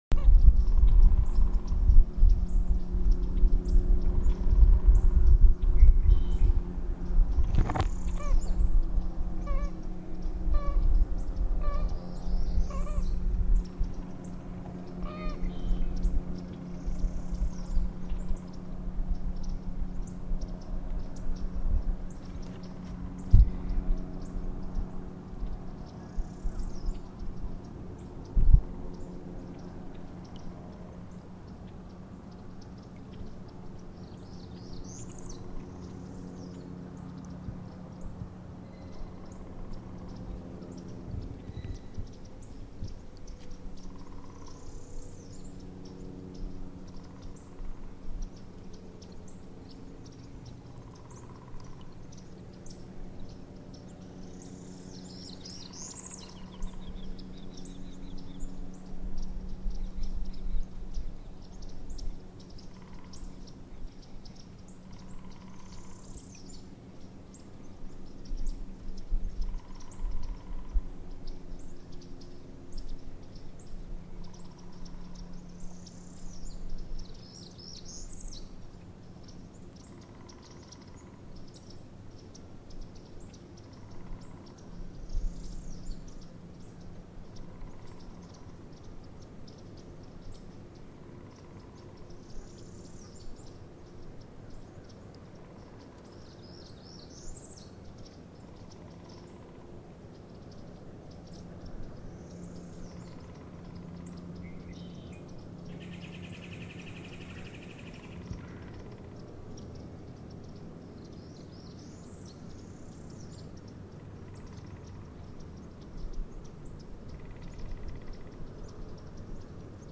On Lake Dora's south shore, waterfowl greet paddlers fleeing the din of development. Listen to four minutes and thirty three seconds on the water: Heading back to the north shore, kayaks rise and fall across seaplane and pontoon wake.
lake-dora-waterfowl.mp3